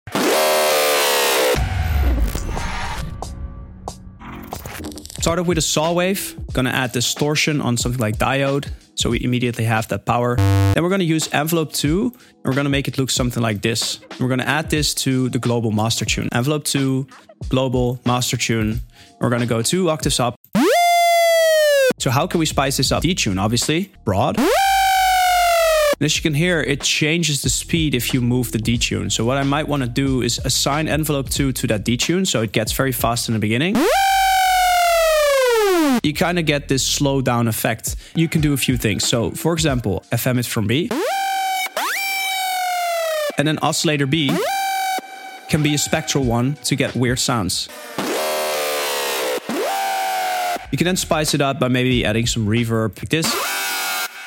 Divebomb Screeches…